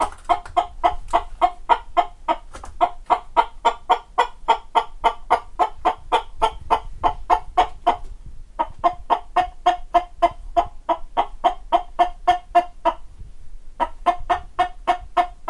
鸡叫声 咯咯声
描述：模仿鸡咯咯
标签： 母鸡 母鸡 早晨 公鸡 村庄 动物 鸟类 咯咯 农村 唤醒 公鸡 咕咕 农场 获得 之后 现场记录
声道立体声